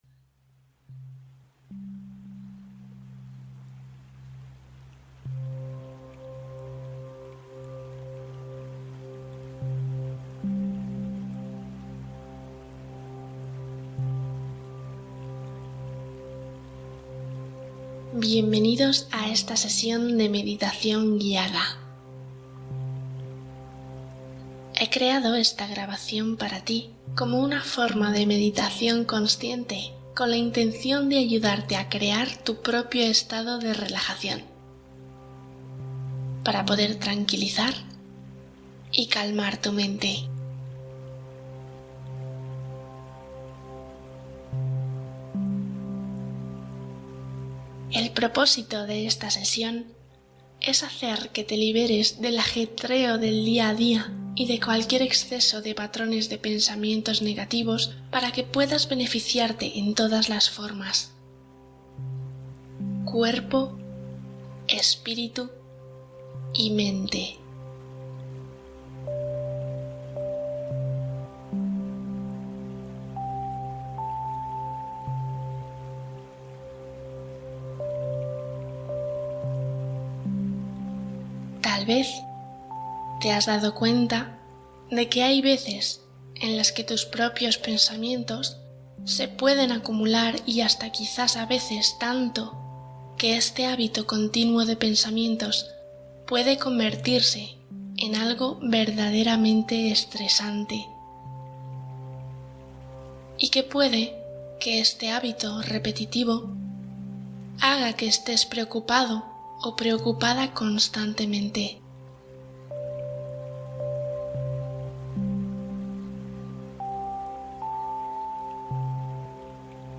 Meditación para liberar pensamientos excesivos y aliviar la mente